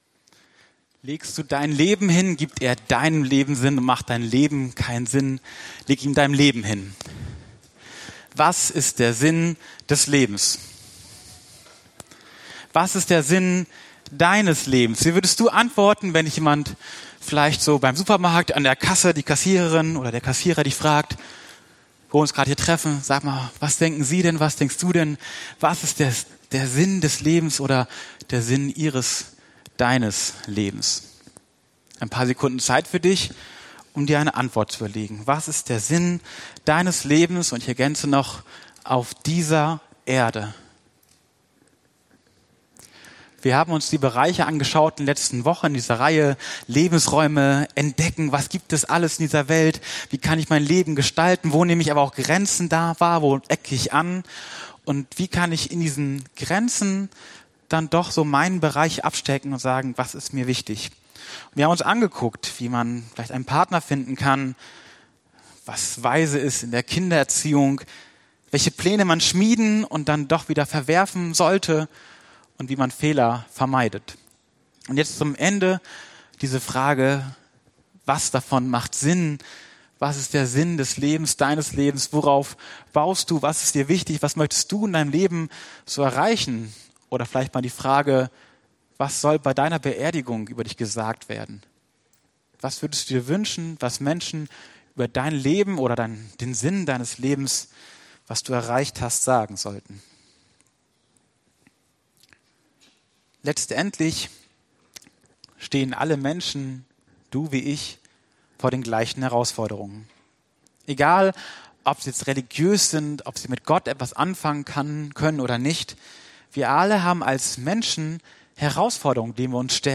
Prediger Dienstart: Predigt Themen